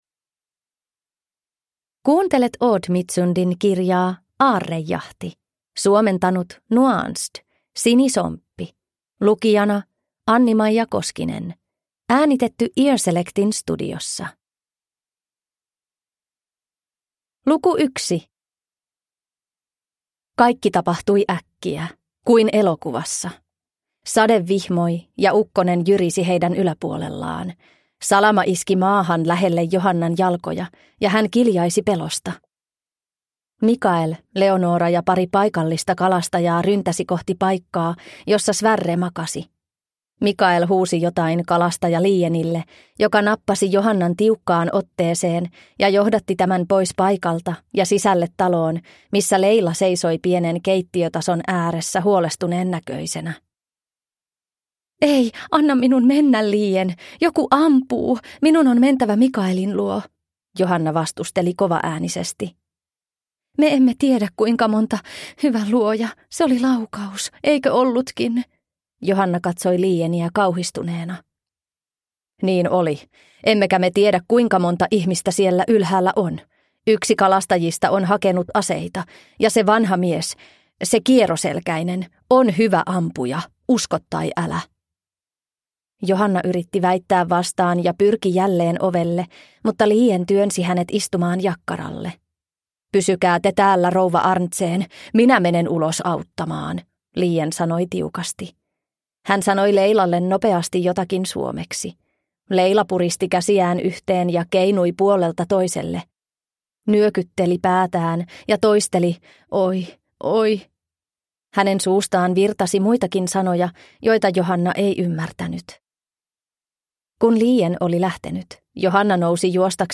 Aarrejahti – Ljudbok